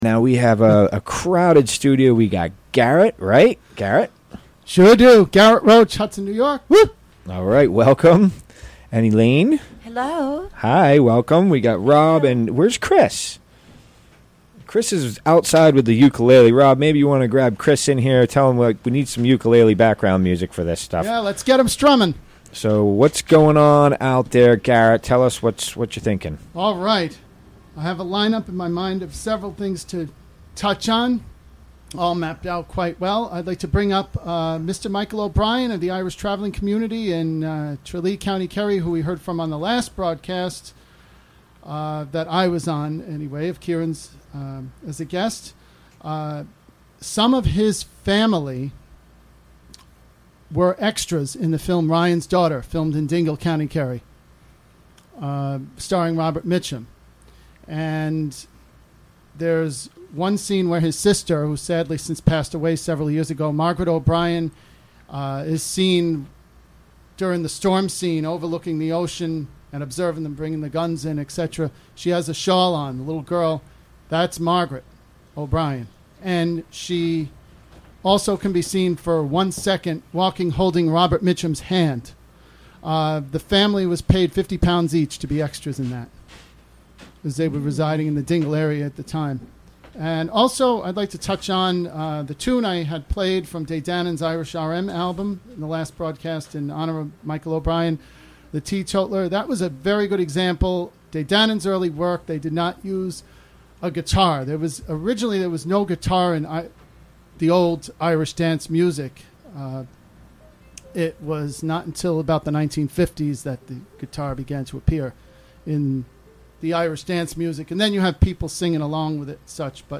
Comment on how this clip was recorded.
Recorded during the WGXC Afternoon Show Monday, July 31, 2017.